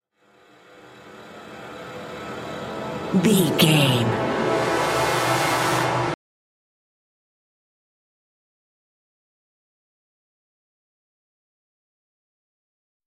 Scary Sweep Lo
Sound Effects
Atonal
scary
ominous
eerie
synth
ambience
pads